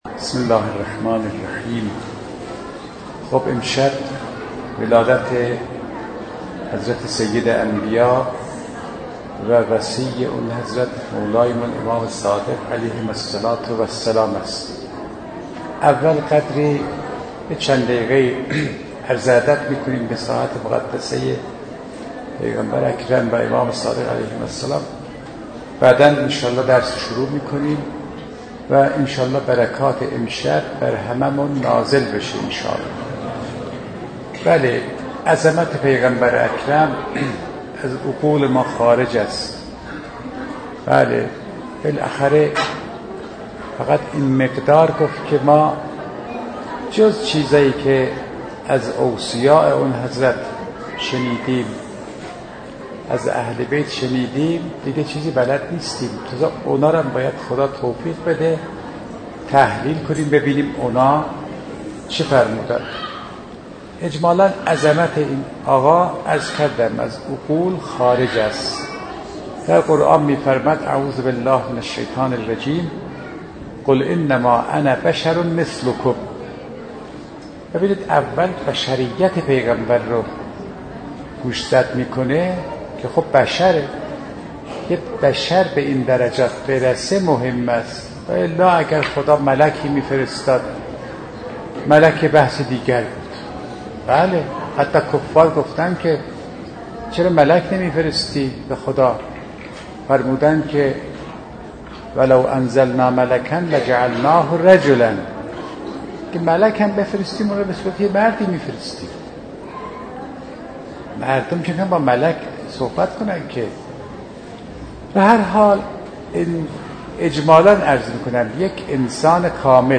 * در ادامه می‌توانید صوت کامل این سخنرانی را بشنوید: انتهای پیام/*